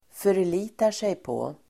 Uttal: [för_l'i:tar_sejpå:]